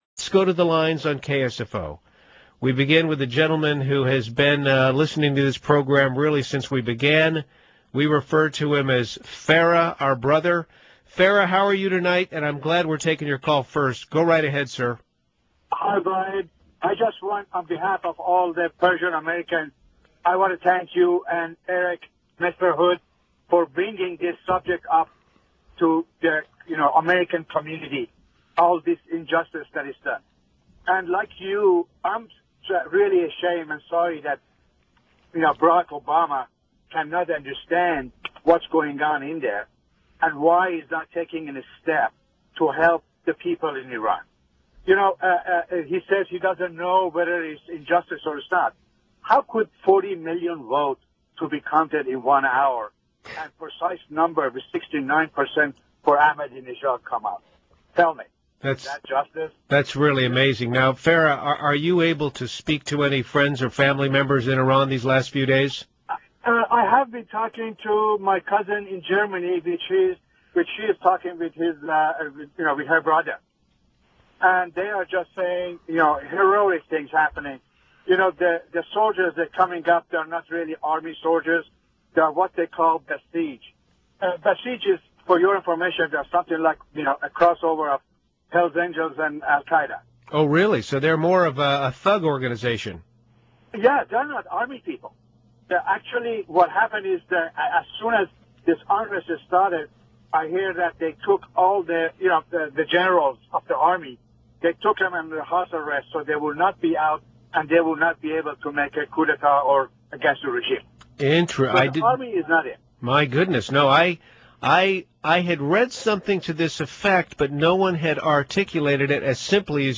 Here is the audio clip of the entire call.